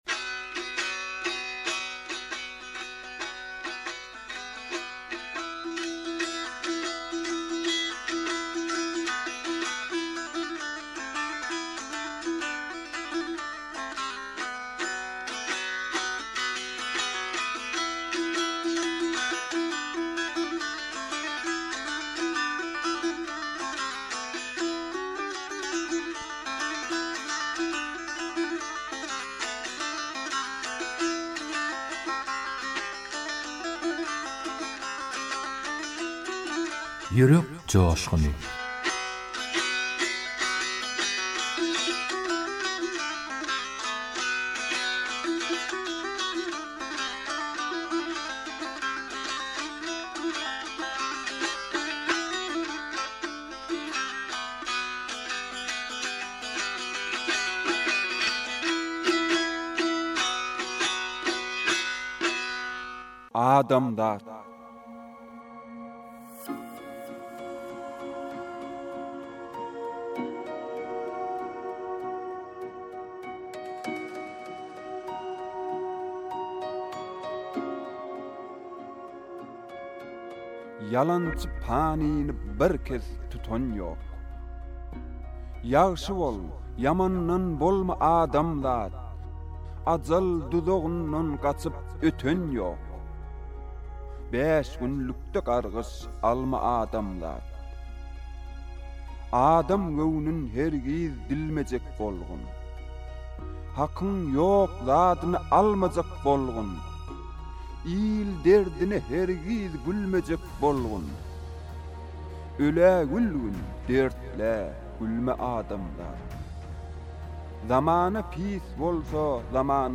turkmen goşgy owaz aýdym